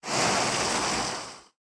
foundry-pour-1.ogg